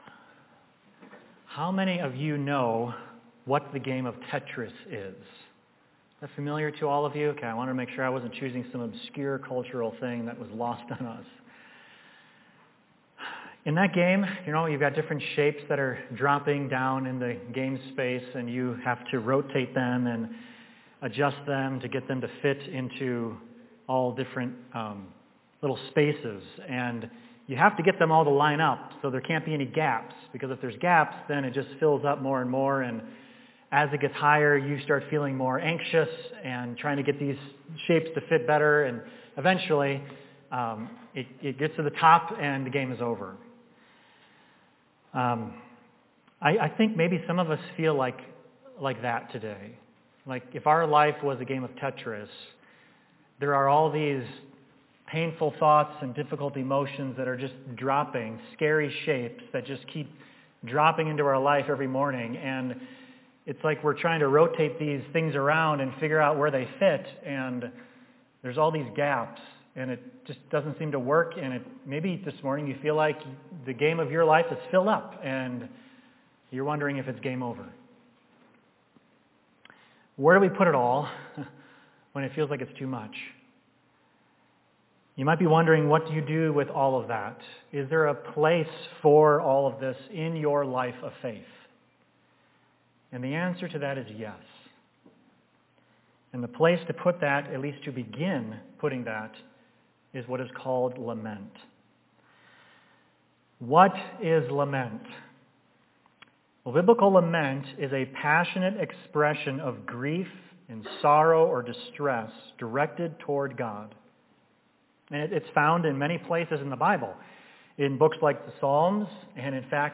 Sermons
Service: Sunday Morning